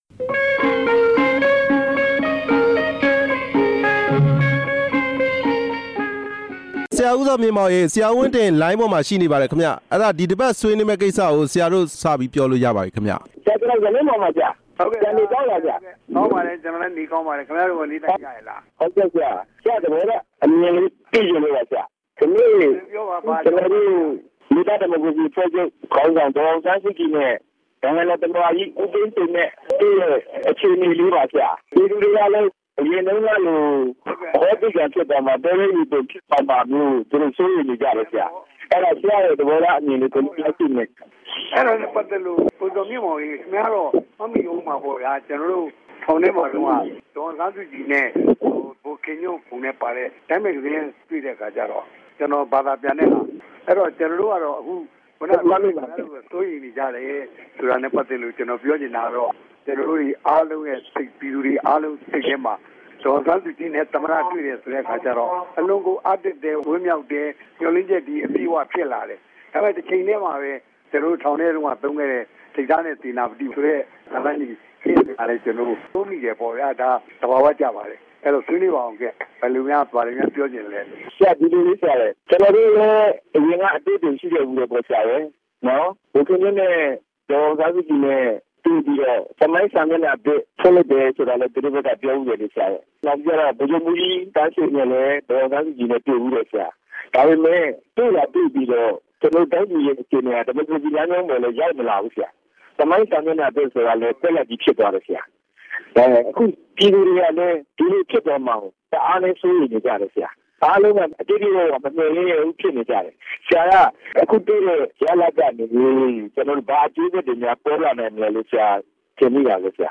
ဦးဝင်းတင်နှင့် စကားပြောခြင်း